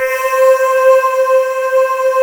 Index of /90_sSampleCDs/USB Soundscan vol.28 - Choir Acoustic & Synth [AKAI] 1CD/Partition D/17-GYRVOC 3D